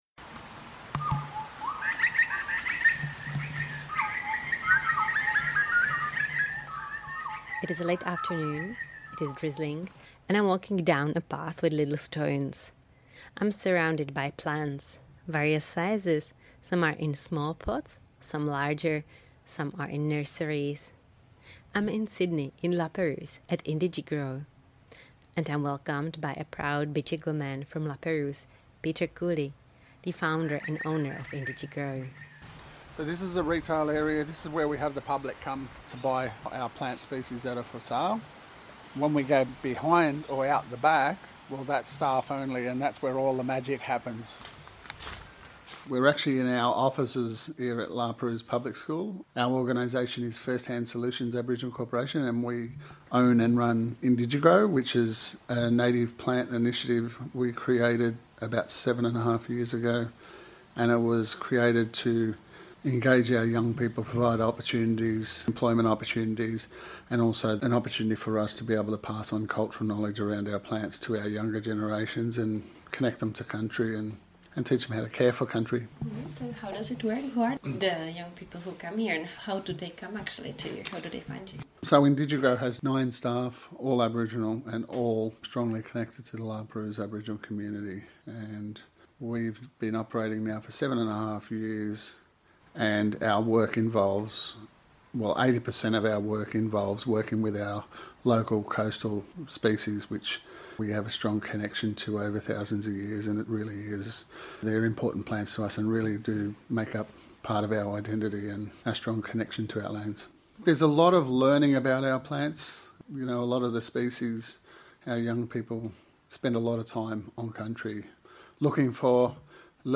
As we step to the tranquil gardens at La Perouse public school, we can smell the rain, hear the birds singing and walk past flowerbeds with various plants.